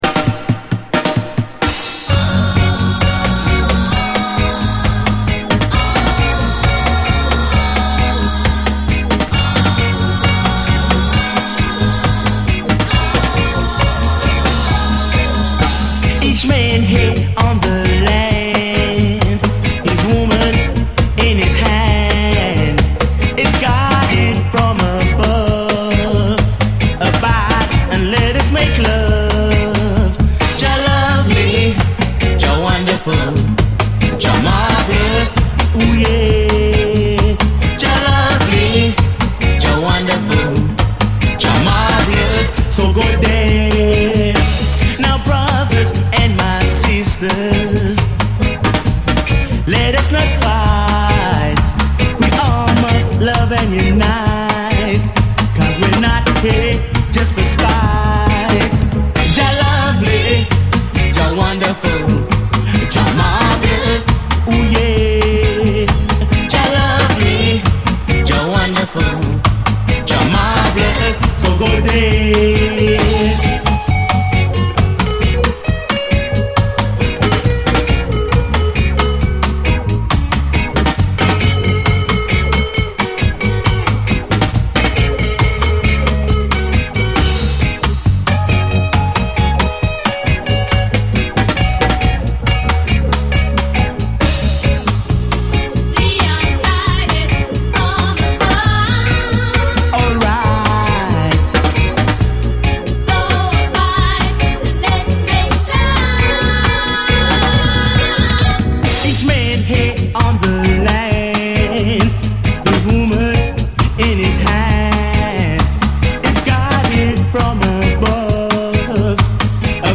REGGAE
至福のステッパー・ラヴァーズ
盤見た目はスレ目立ちますが実際は非常に綺麗に聴けます
しっかりとファットな演奏、そしてキュート極まるヴォーカルの前に崩れ落ちるのみ。後半に収録されたダブもまた素晴らしい。